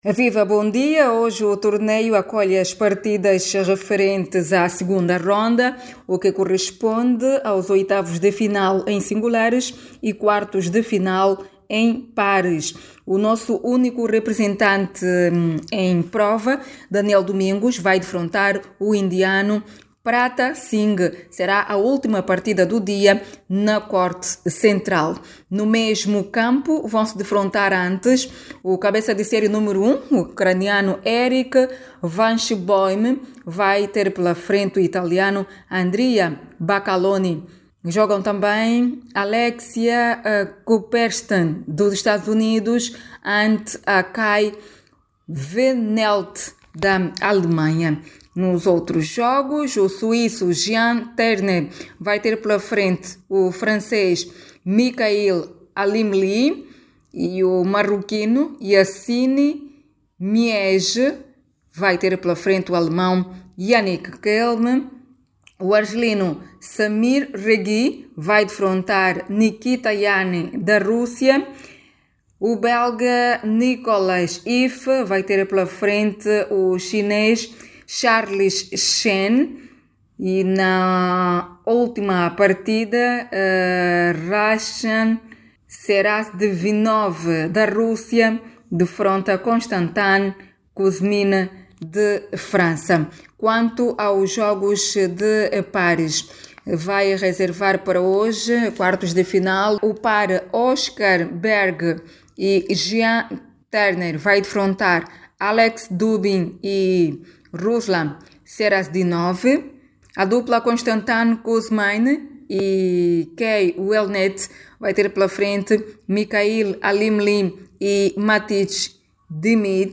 Saiba mais dados no áudio abaixo com a repórter